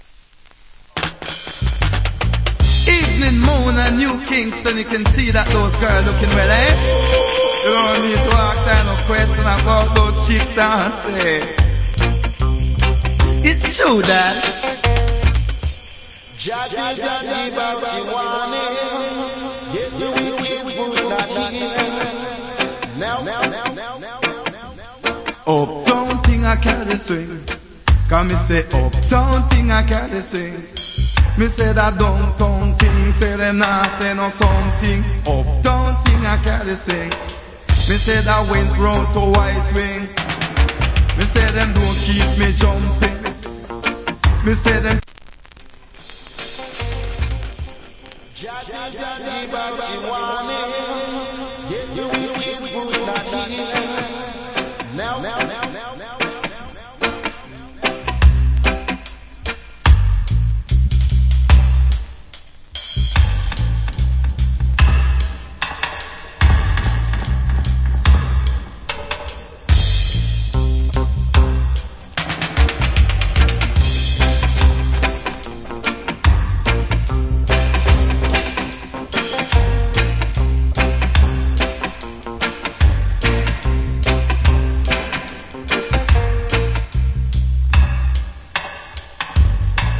Cool DJ.